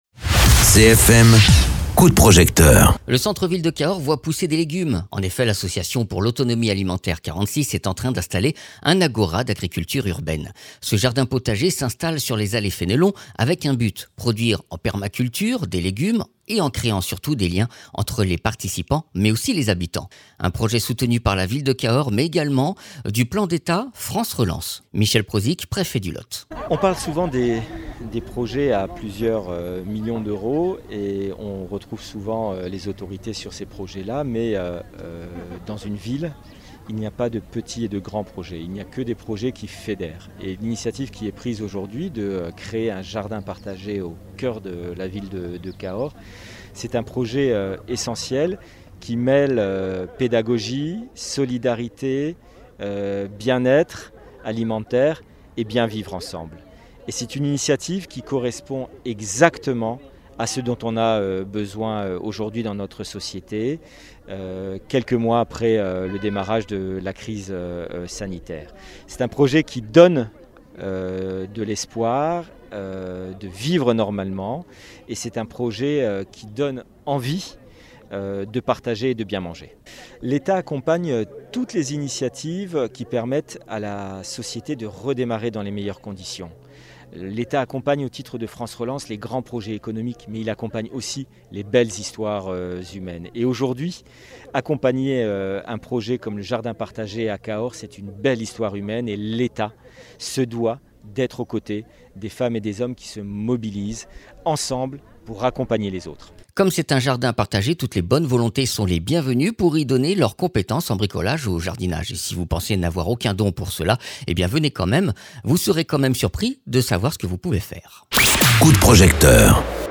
Interviews
Invité(s) : Michel Prosic, préfet du Lot